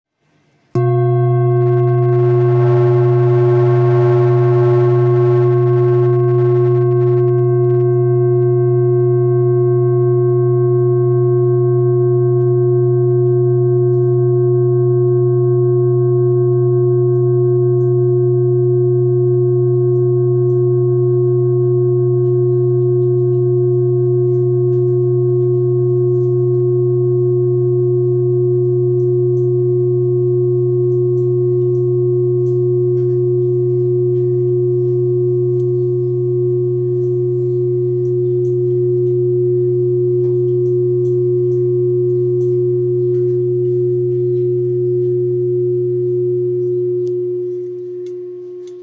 Handmade Singing Bowls-30400
Singing Bowl, Buddhist Hand Beaten, with Fine Etching Carving, Select Accessories
Material Seven Bronze Metal